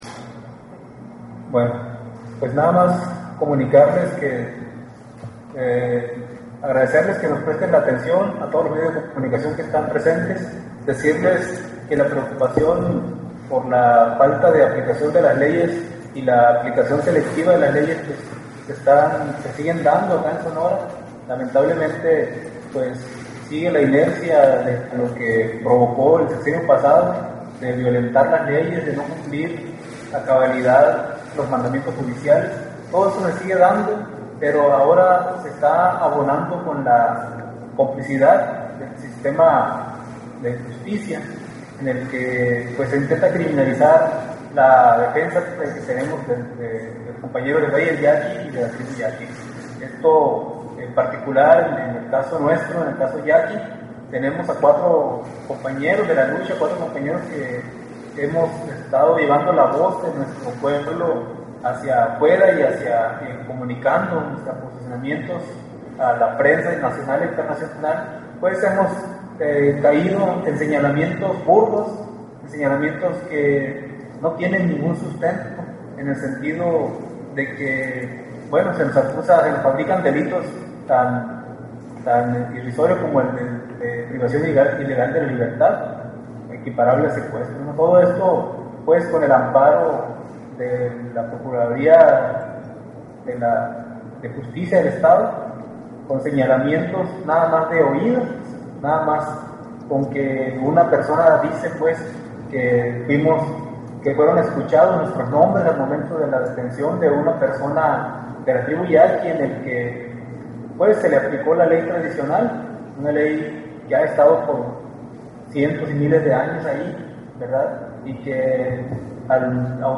En conclusión los conferencistas concuerdan con  tres puntos:  en México las obras se realizan sin estudios previos de impacto ambiental, se desacata las sentencias de la SCJN y la nueva ley de amparo no funciona debidamente.